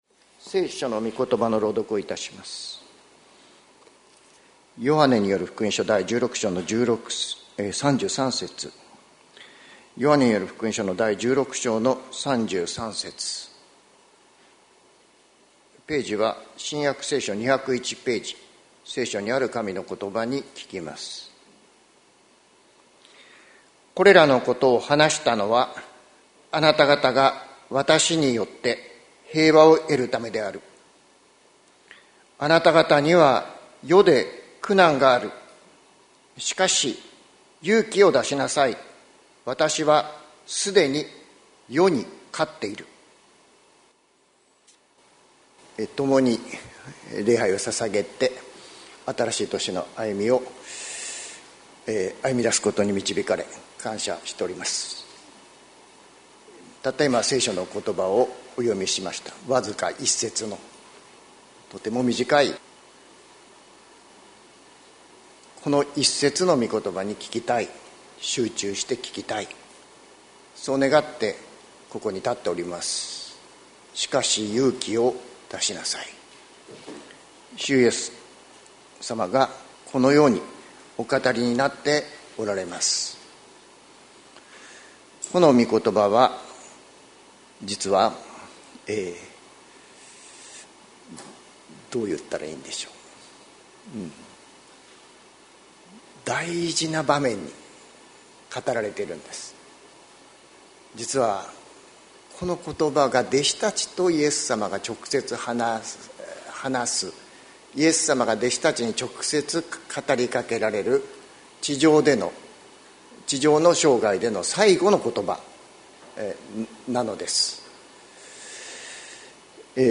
礼拝説教